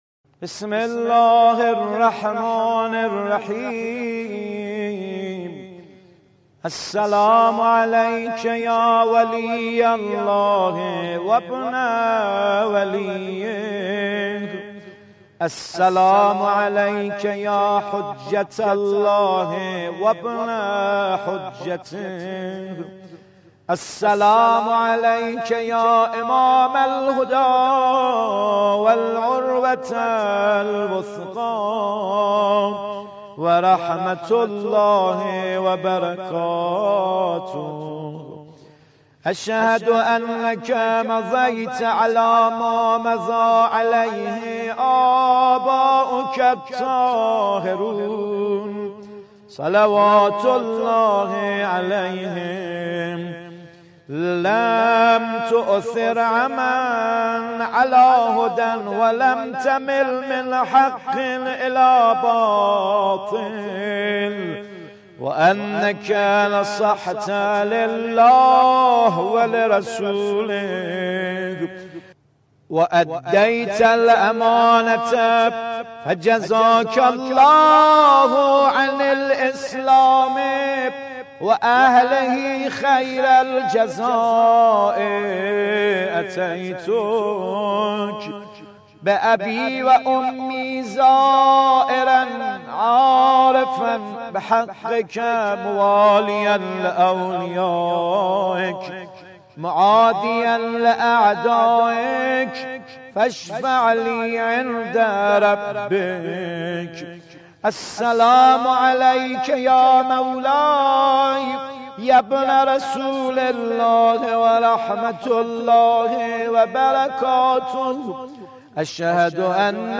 زیارت مختصر